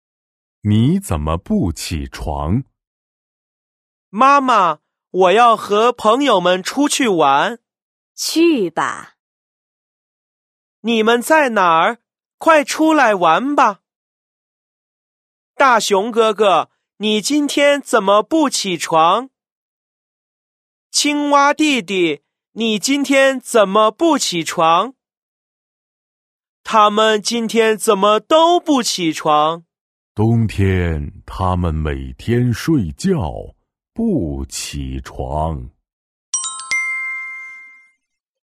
5⃣ Đọc chuyện.